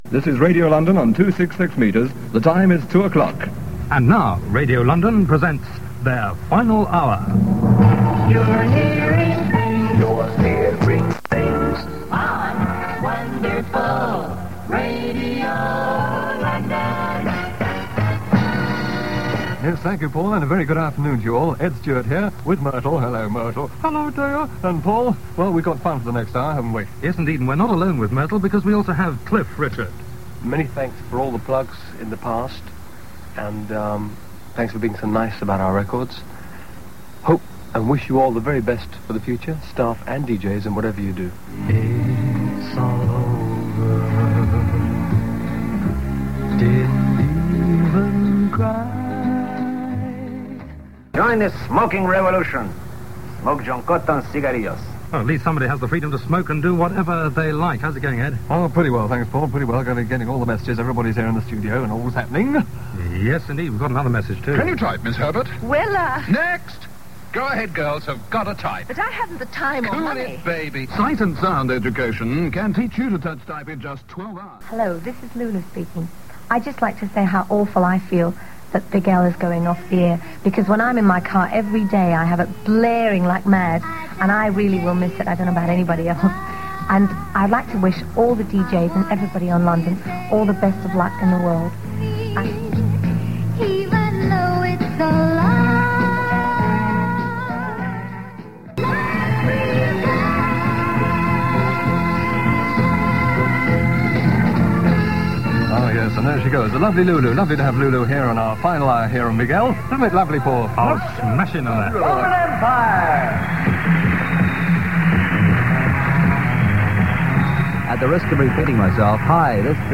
Disc-jockeys and recording stars paid their respects in a special show pre-recorded the previous evening.